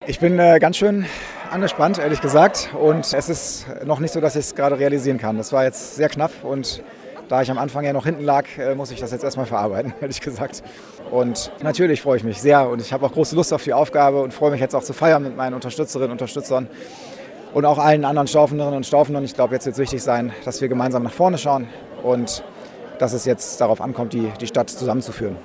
Bis zuletzt war es spannend auf dem Marktplatz.
Benjamin Bröcker kurz nach dem Wahlsieg: